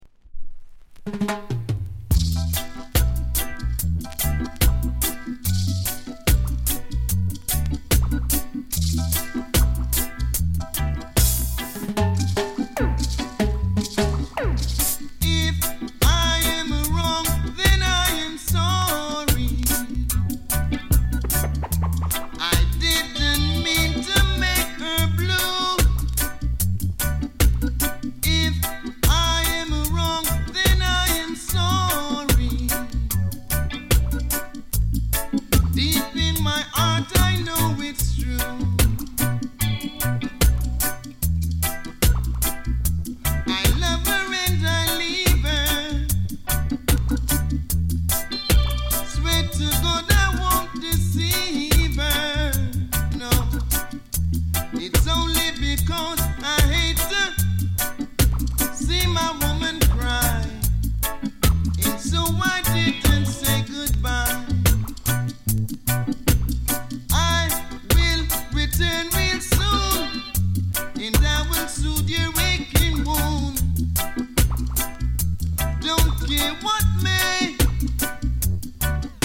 高音質
B面 音に影響ない 軽いセンターずれ。